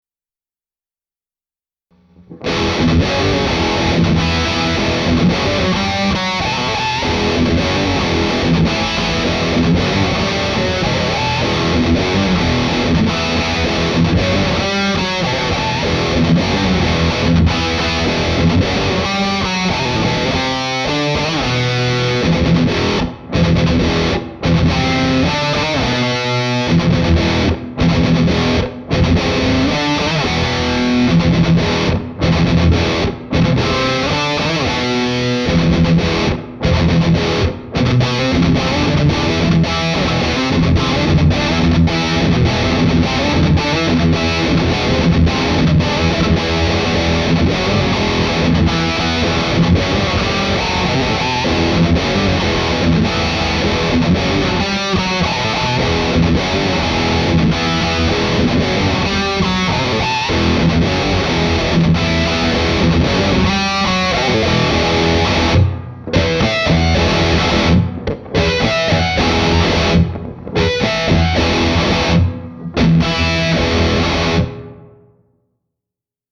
He recorded them using a 1X12 cab with a Celestion G12H.  The microphones used were Shure SM57 and Royer 121 (used together).  The guitars were a PRS EG and a PRS Baritone (used on last track).  Since they were recorded in a sound-deadened studio and close-miced, some reverb was added.